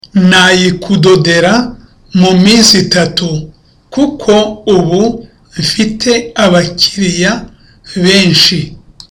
(With confidence.)